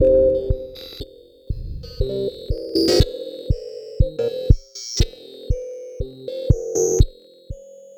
Abstract Rhythm 08.wav